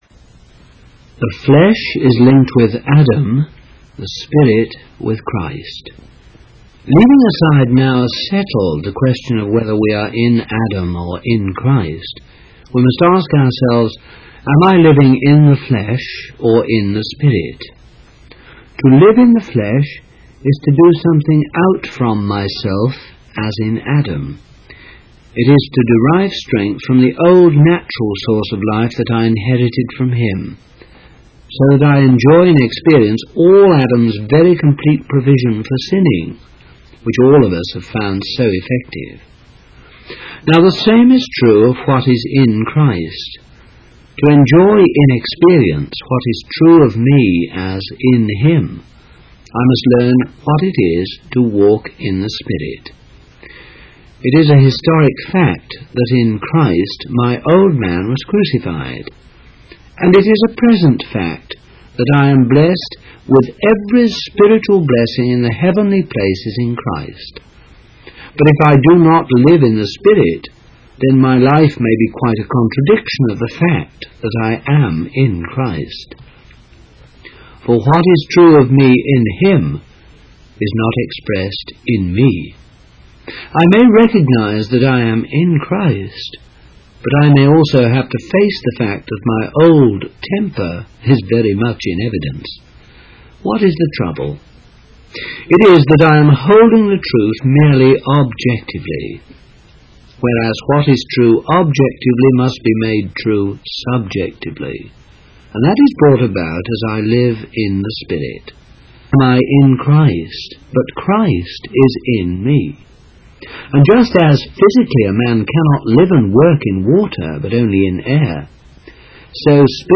Body in Christ (Reading) by Watchman Nee | SermonIndex